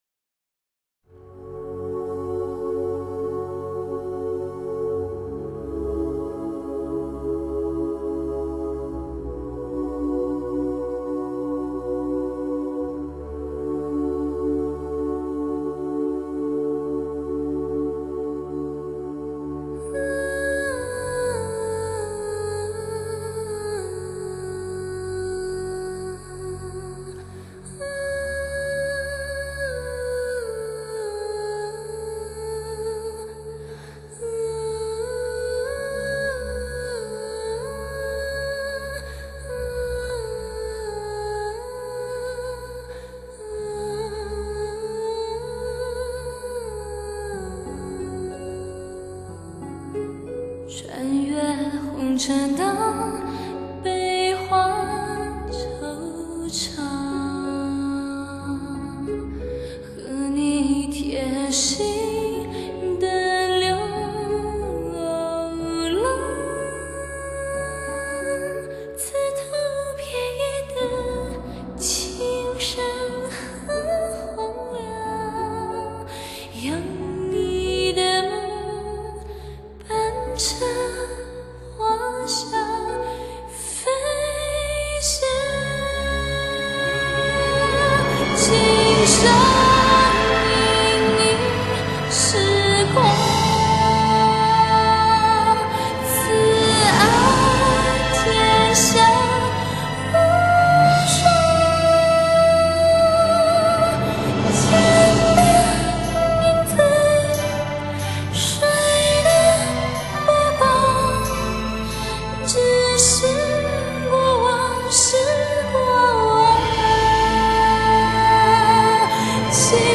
清润甜美的天籁女声